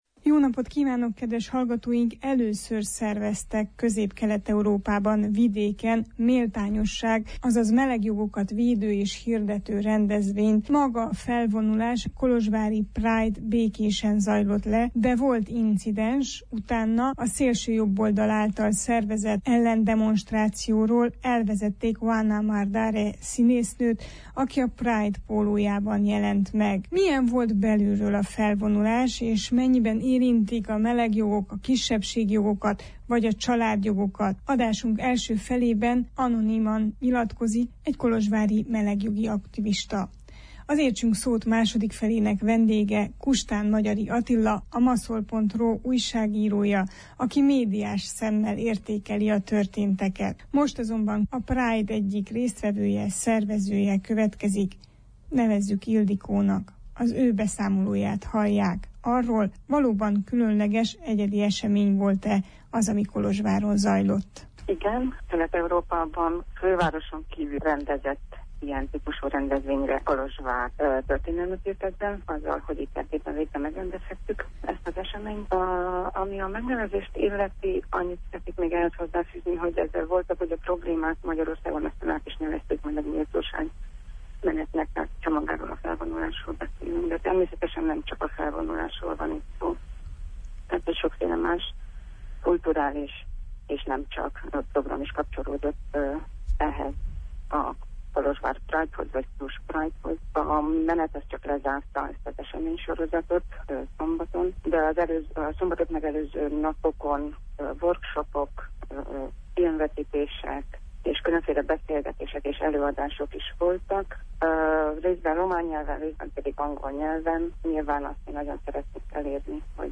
Történelmet írtak Kolozsváron az első, Közép-Kelet Európában szervezett méltányosság napi rendezvénnyel, de nem feltétlenül csak a felvonulás, hanem a közösségi munka, a folyamatos tájékoztatás és párbeszéd jelenti a hatékony jogvédelmet. Anonim melegjogi aktivista összegzi az erdélyi tapasztalatokat, terveket